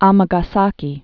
mə-gä-säkē)